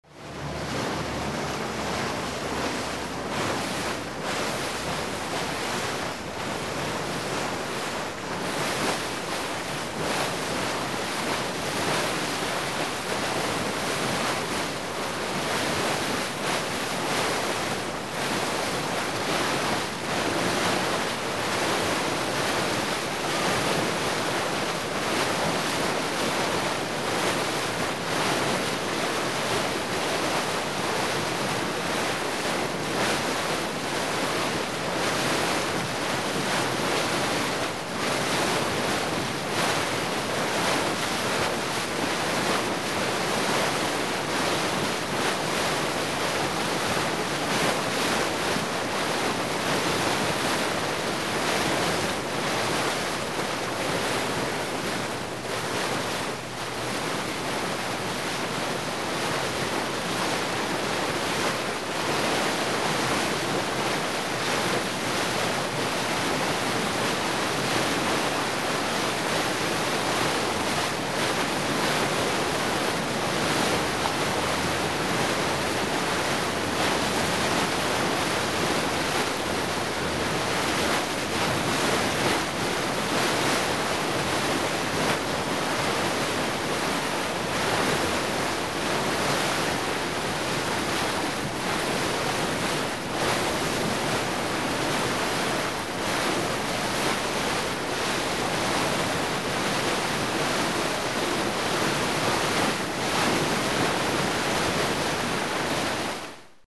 На этой странице собраны разнообразные звуки воды: журчание ручья, шум прибоя, капли дождя и плеск водопада.
Судно режет волны